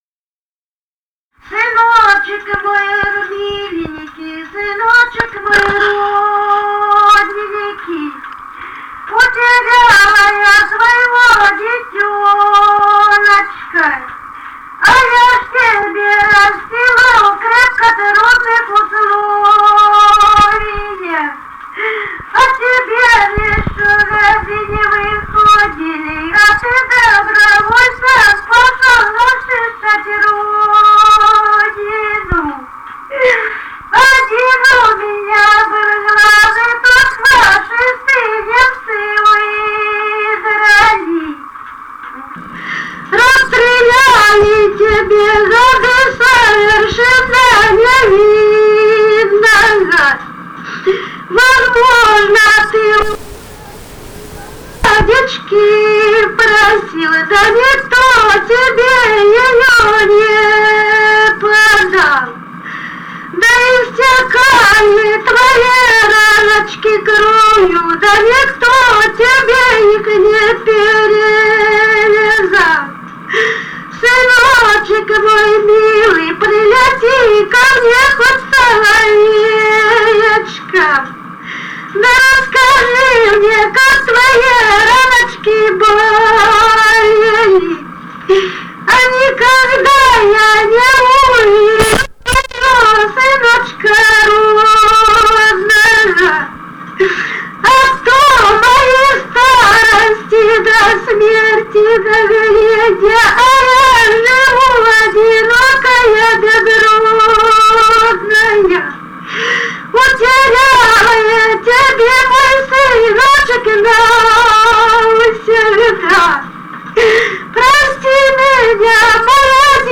Музыкальный фольклор Климовского района 039. «Сыночек мой размиленький» (похоронное причитание).
Записали участники экспедиции